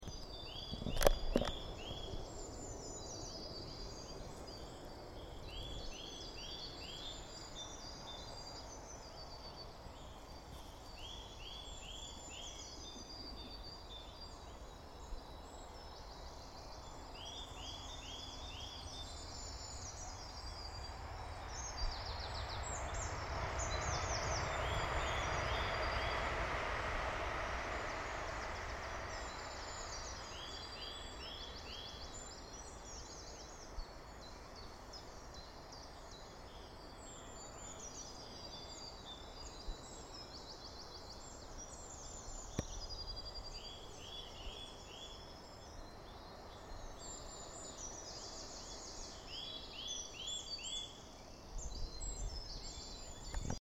Woodland Dawn Chorus
The wood was already alive with song and the sun was yet to rise.
I recorded birdsong along with the passing trains heading into Brighton or up to London. I expect the birds sing more loudly here as they have to compete with this extra noise.
On the audio you can hear a persistant nuthatch, a wren, a chiff chaff, blue tit, great tit and a train passing.